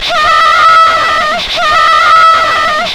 DISTOSCREA-L.wav